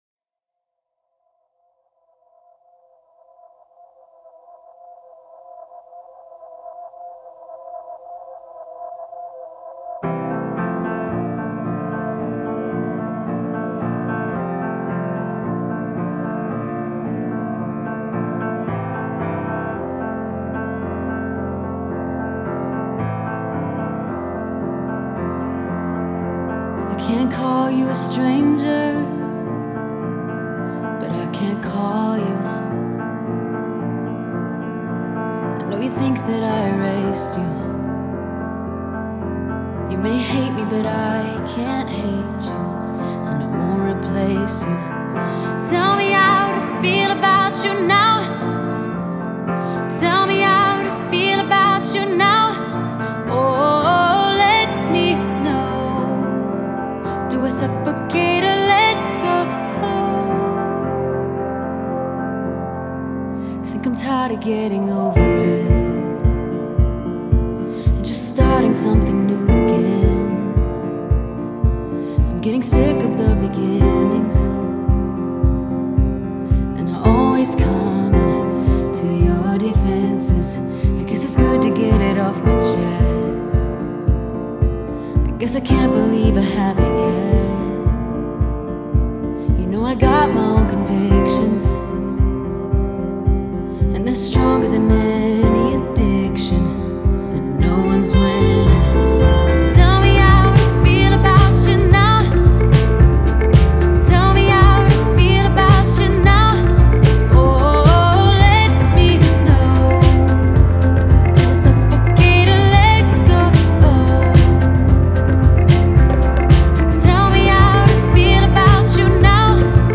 поп-музыка, рок-музыка